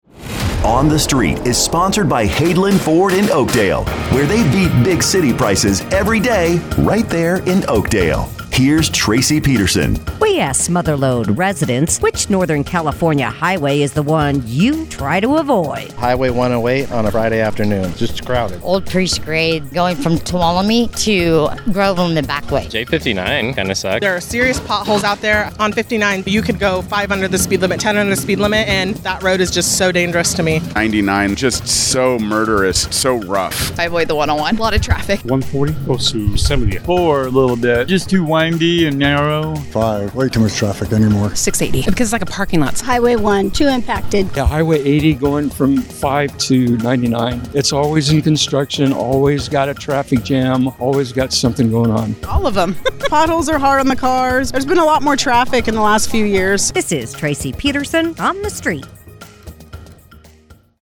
asks Mother Lode residents, “What Northern California highway is the one you try to avoid?”